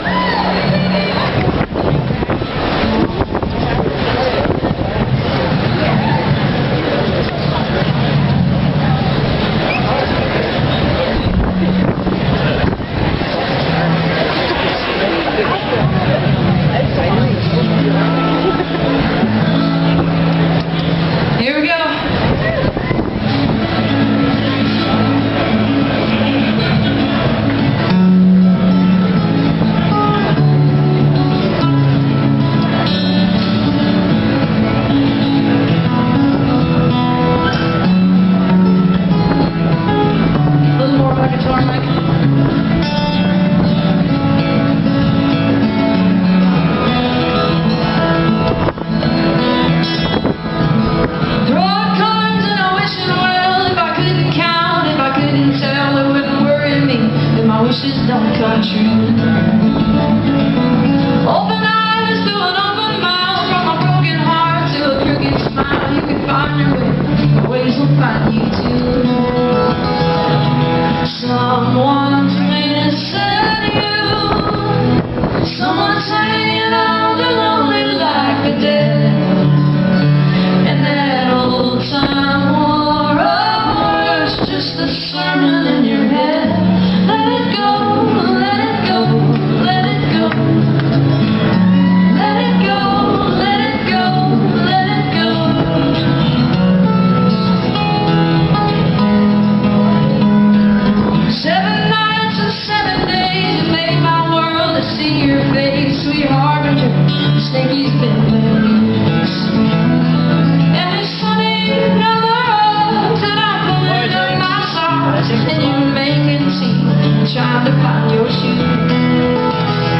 inside rehearsal
live on deck